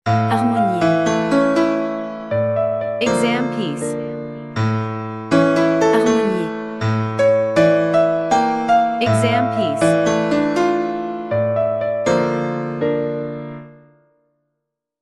• 人声数拍
• 大师演奏范例
我们是钢琴练习教材专家